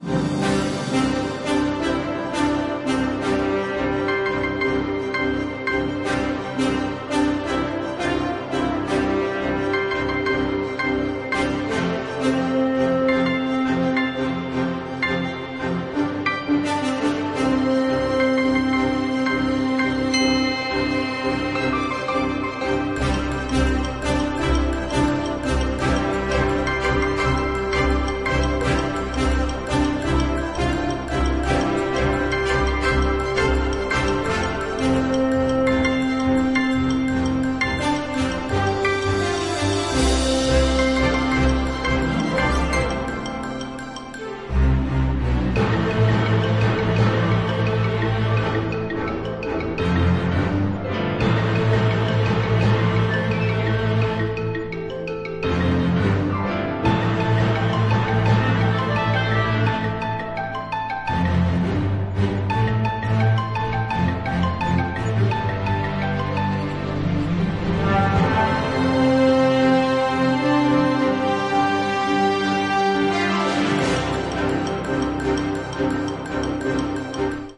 紧张的脉动
描述：电影紧张节奏，但也可用于游戏。